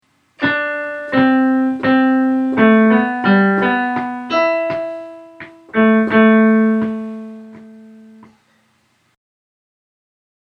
An interesting rhythm, but a little hard to sing. Most of the time I wind up adjusting the purely-random rhythms to “square them off”.